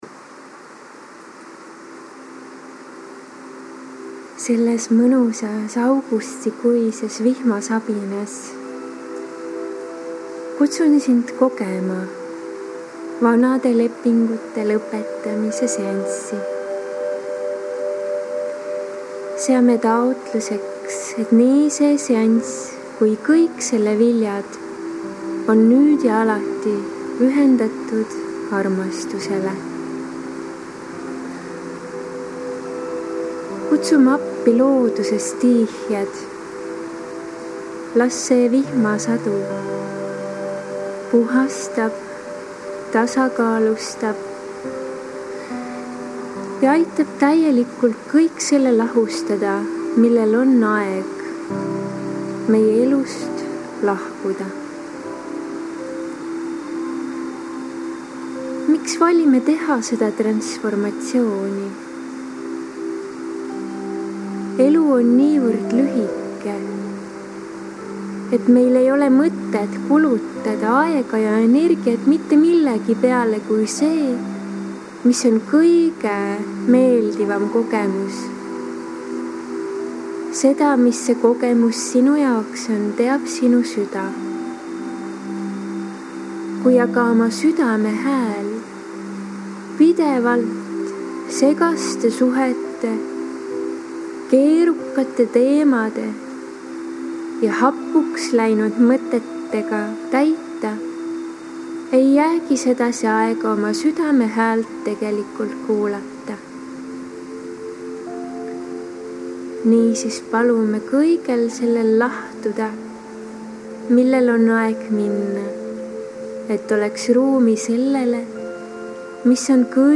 Võid seansi ajal minuga koos taotlusi lausuda või jääda ka lihtsalt rahusse, lõdvestuse ja lubada energiatel läbi enda voolata. Iga kord seda meditatsiooni kuulates saavad puhastatud Su meel, teadvus, keha ja süda.